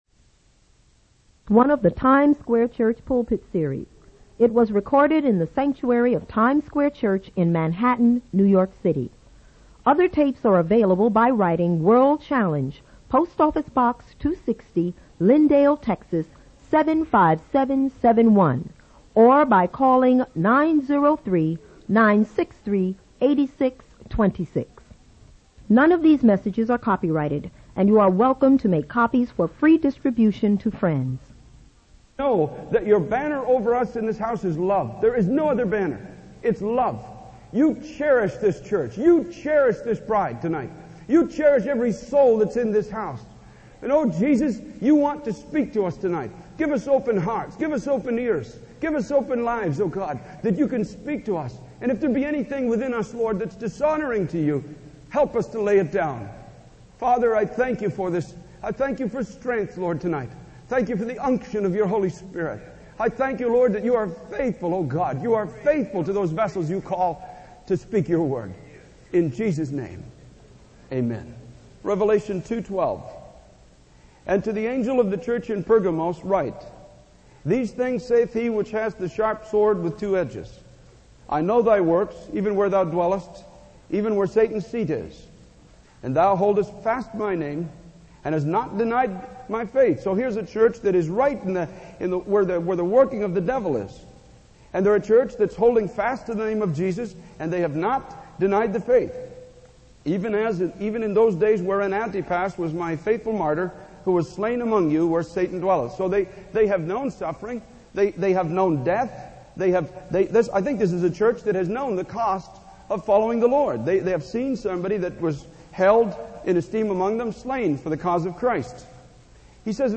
In this sermon, the preacher emphasizes the importance of going beyond personal convenience in order to fulfill God's plans for our lives.
It was recorded in the sanctuary of Times Square Church in Manhattan, New York City.